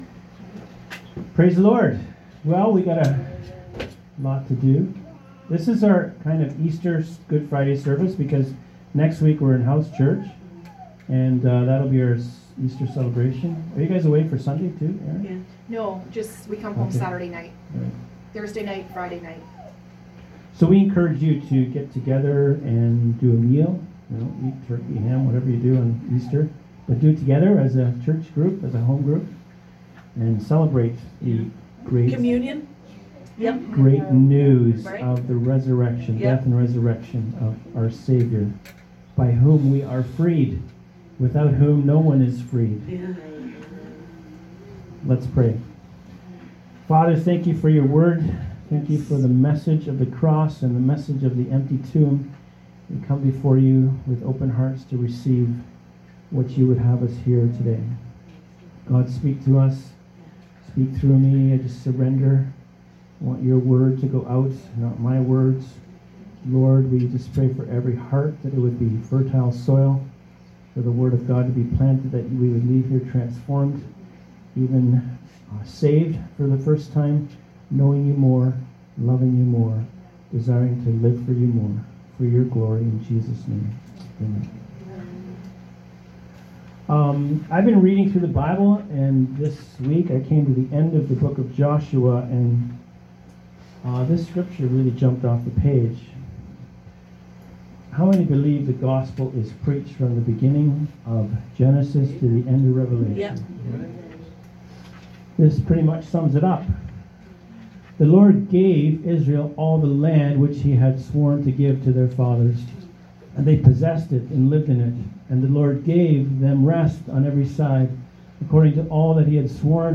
Audio Sermons - Freedom House Church and Healing Centre